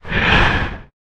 exhale.ogg.mp3